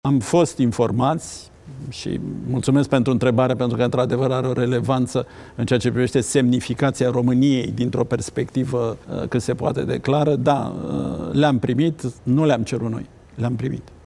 Consilierul prezidențial pentru apărare și siguranță națională, Cristian Diaconesu susține într-un interviu acordat postului Antena 3 că partea americană a „refuzat, în acest moment, dar nu avem garanții”.